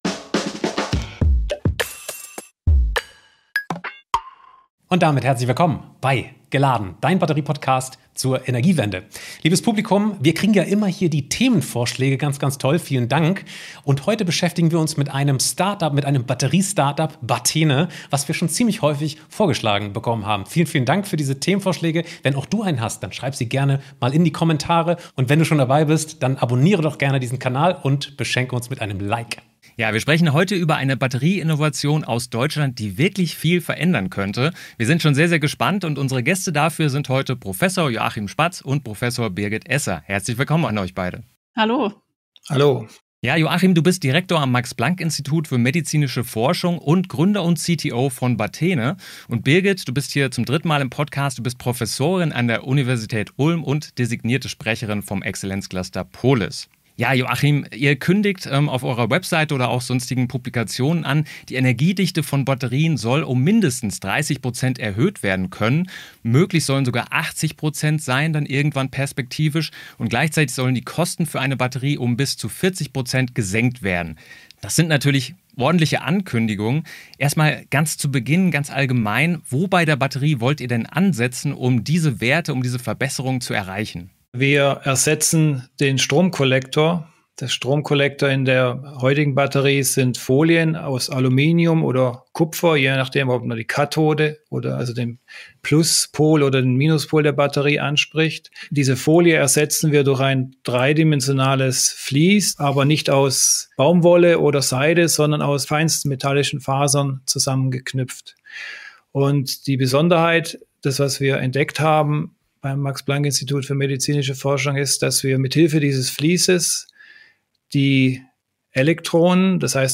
Geladen - der Batteriepodcast zur Energiewende